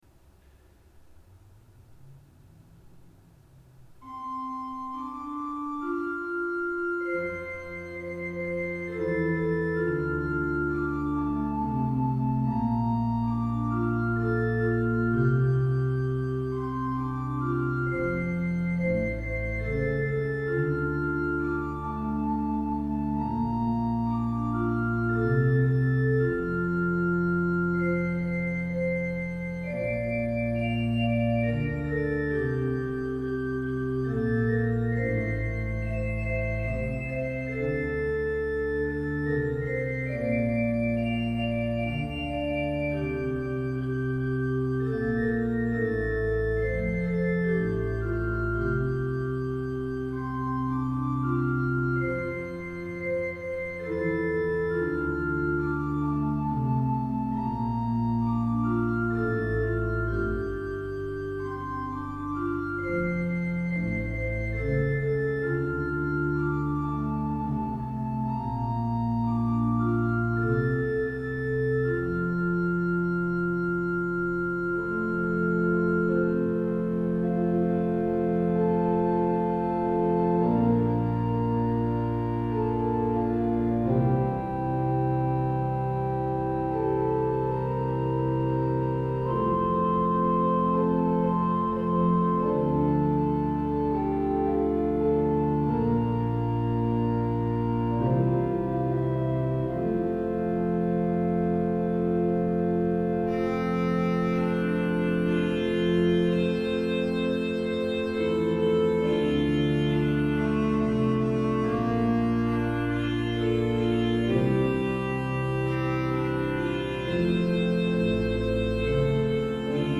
Sandefjord Kirke   ZOOM H4n 06.09.2015